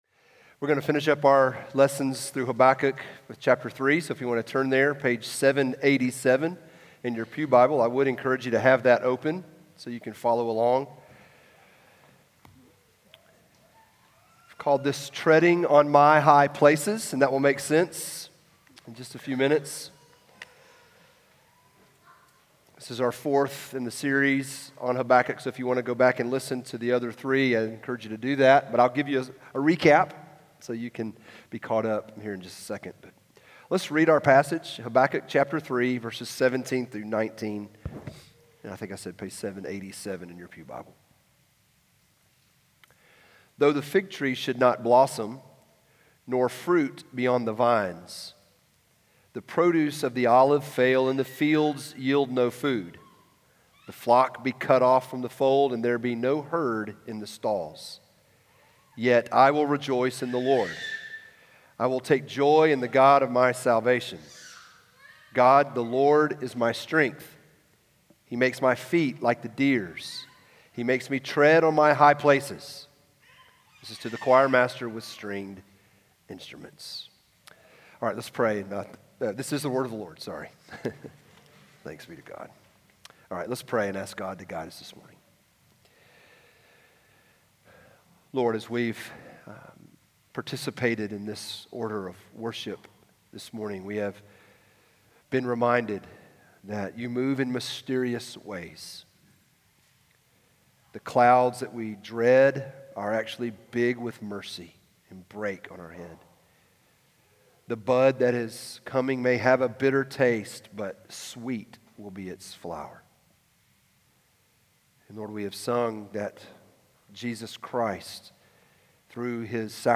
Treading On My High Places TCPC Sermon Audio podcast